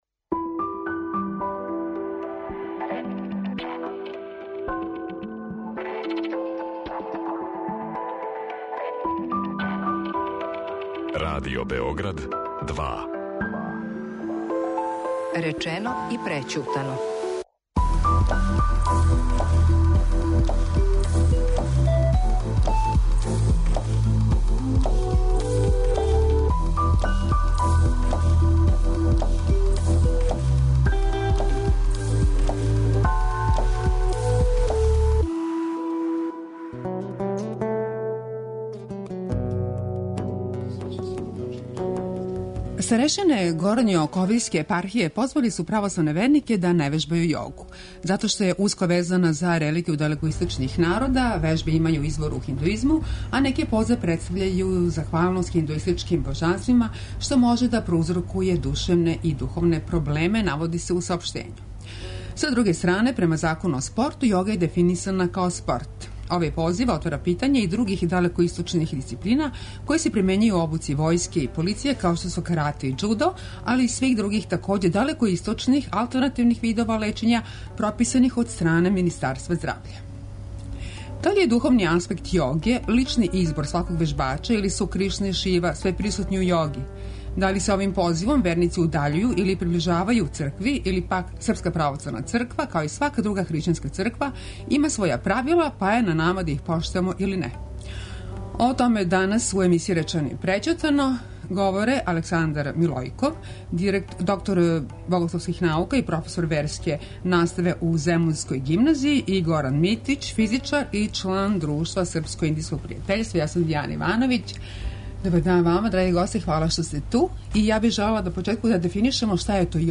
Аудио подкаст Радио Београд 2